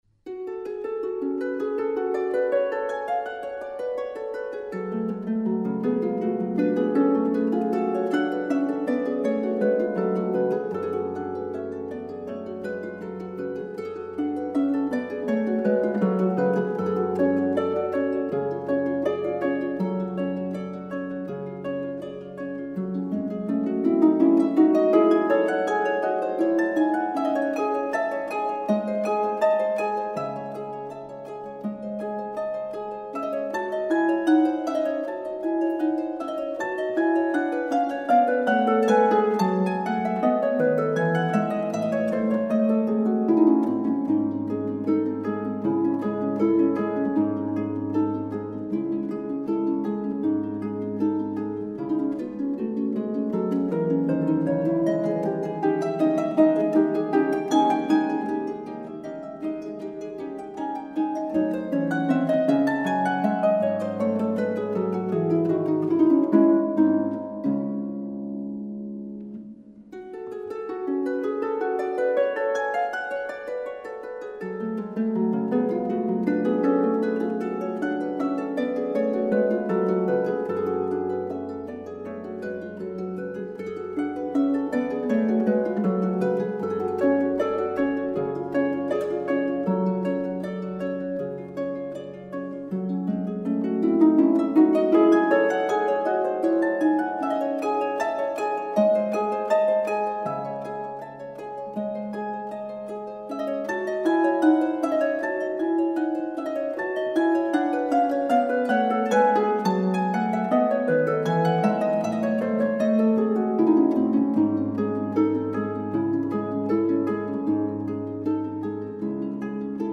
Harfe
D. Scarlatti: Sonate f-Moll K 25